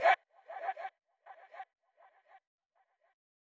vocals.wav